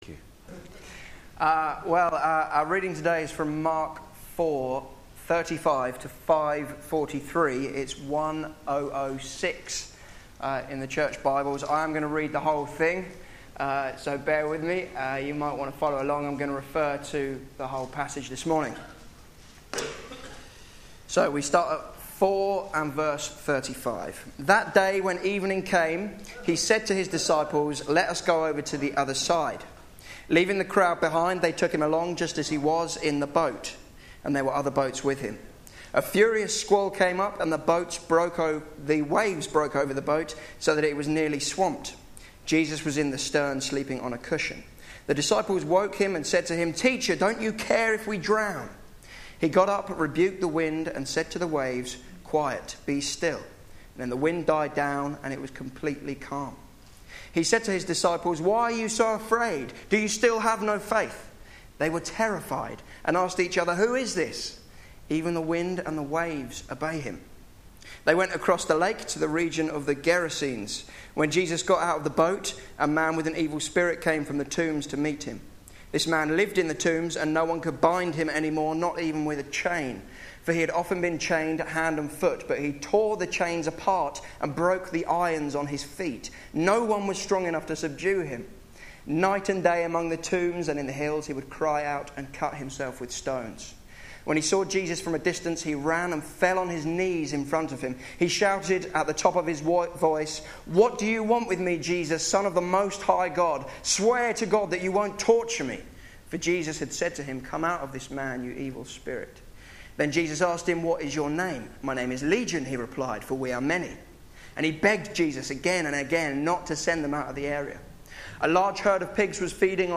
Sunday Service
Theme: Afraid and amazed Sermon